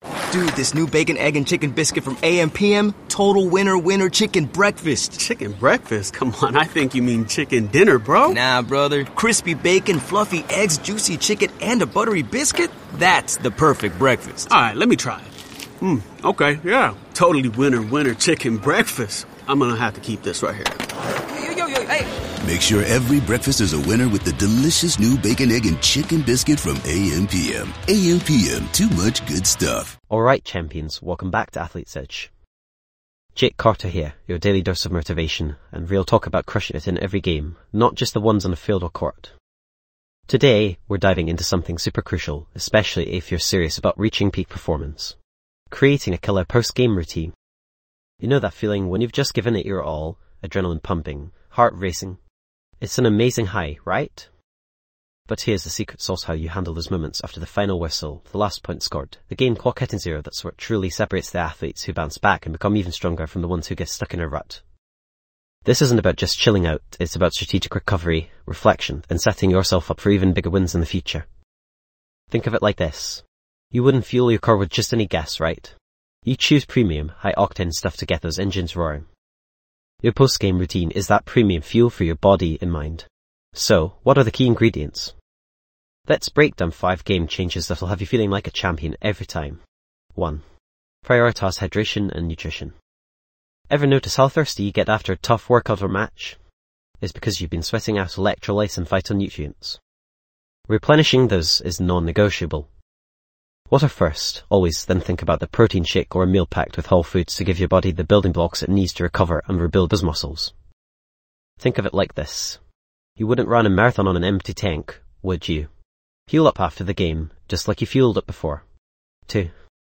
Podcast Category:. Sports & Recreation Motivational
This podcast is created with the help of advanced AI to deliver thoughtful affirmations and positive messages just for you.